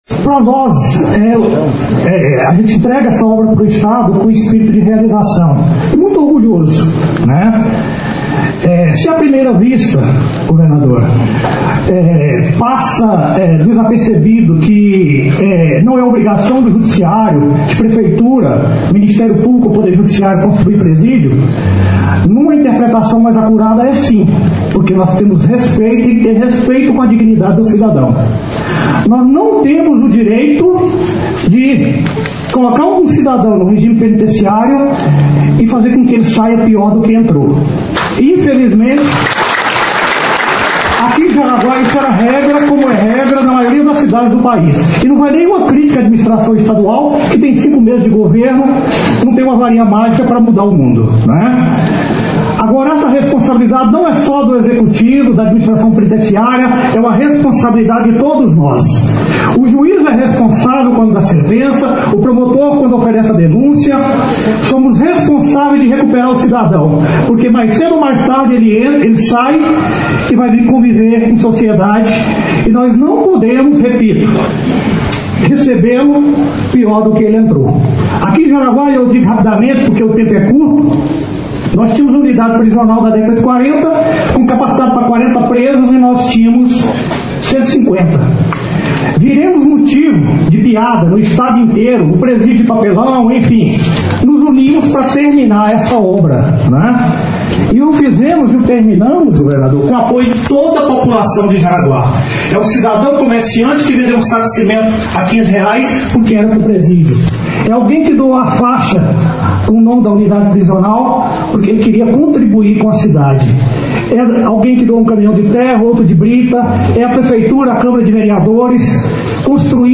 Ouça a declaração do Promotor Everaldo Sebastião: